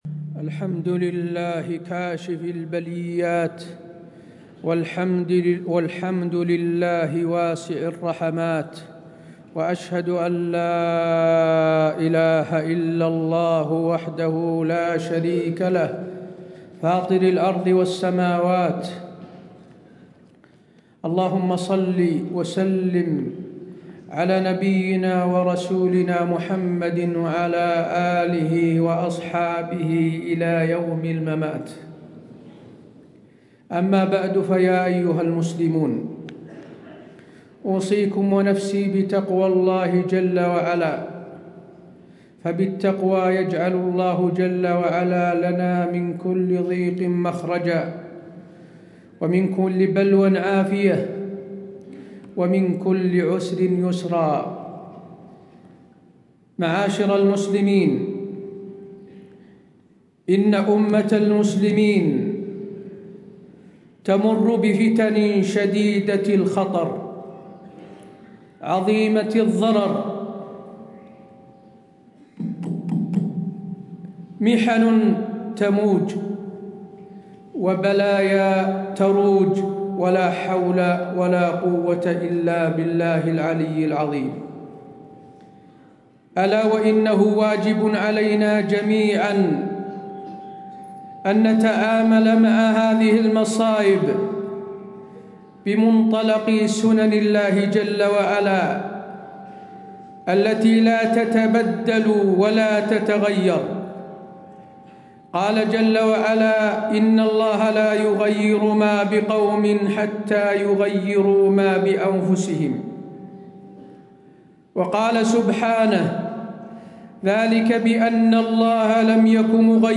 تاريخ النشر ٥ رجب ١٤٣٦ هـ المكان: المسجد النبوي الشيخ: فضيلة الشيخ د. حسين بن عبدالعزيز آل الشيخ فضيلة الشيخ د. حسين بن عبدالعزيز آل الشيخ أسباب دفع العقوبات